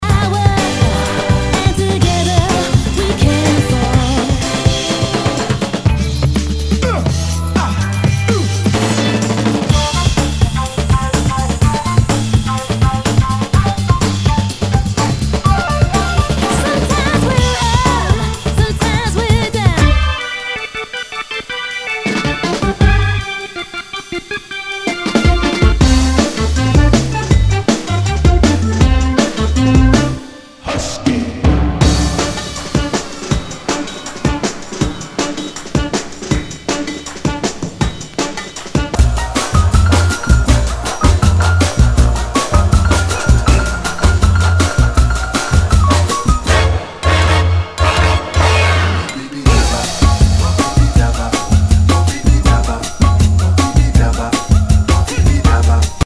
Nu- Jazz/BREAK BEATS
ナイス！ファンキー・ブレイクビーツ！！